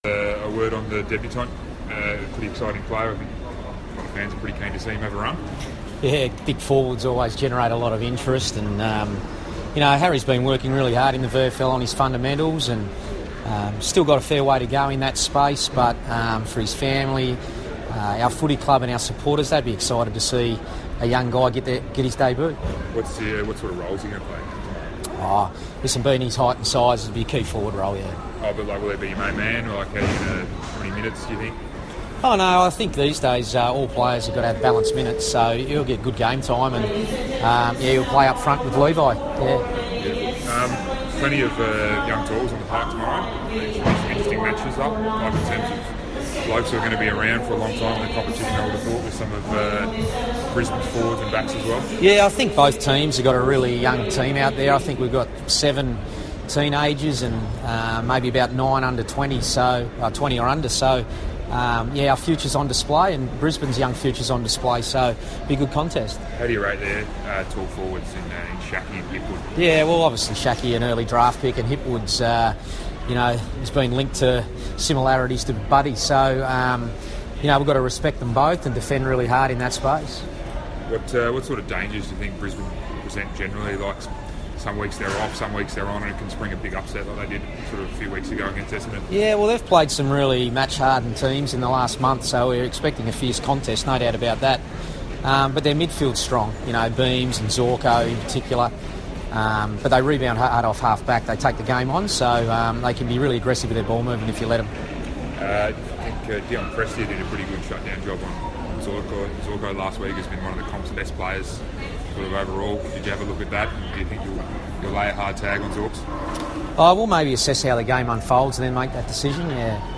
Brendon Bolton press conference - July 22
Carlton coach Brendon Bolton speaks to the media upon arrival at Brisbane Airport.